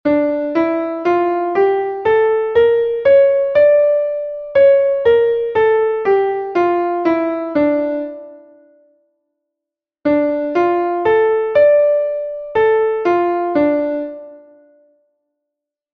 Entoación a capella
Melodía 2/4 en Re m
Escala e arpexio:
escala_aregio_re_menor.mp3